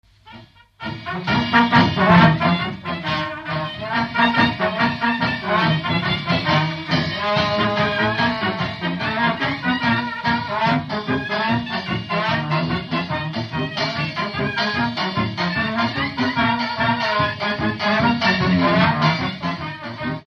Dallampélda: Hangszeres felvétel
Alföld - Csongrád vm. - Sándorfalva
szárnykürt (B)
kalrinét (Esz)
bariton (B)
helikon (F)
nagydob
Műfaj: Oláhos
Stílus: 7. Régies kisambitusú dallamok
Kadencia: b3 (1) b3 1